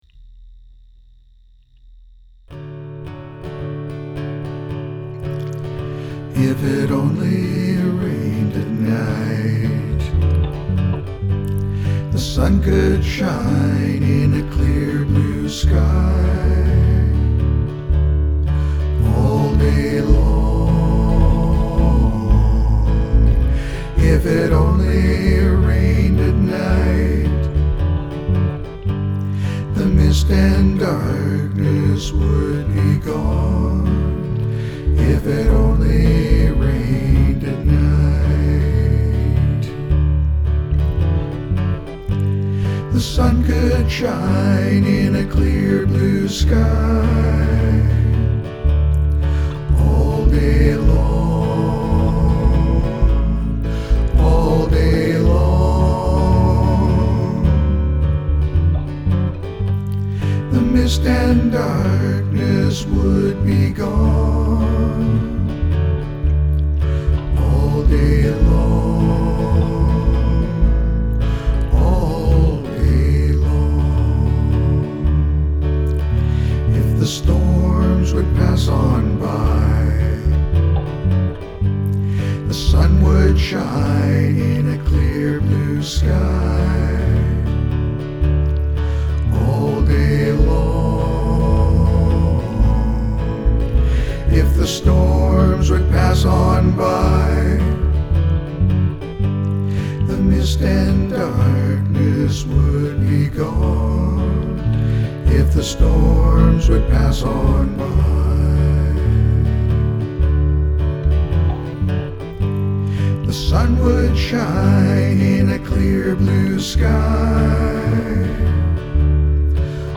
All vocals and instrumentation is by me.